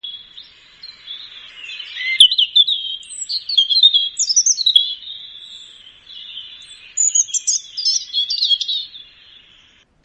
Canto dell’uccellino
ucellino.mp3